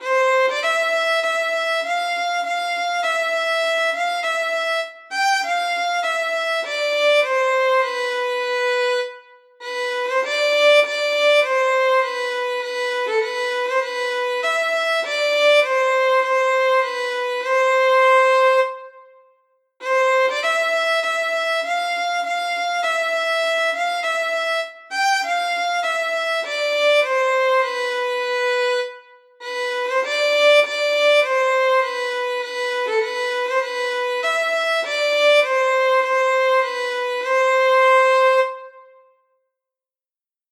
Audio fiddle of “Resurrection” ballad